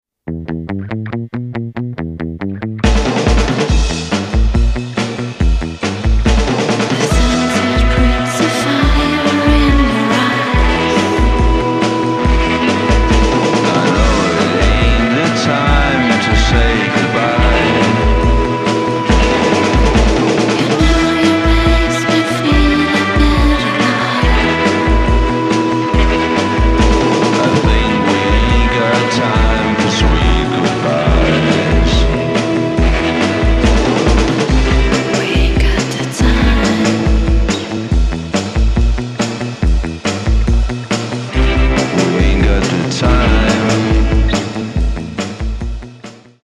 analoge Keyboards, Synthies und Effektgeräte
klassischem 60s Frauengesang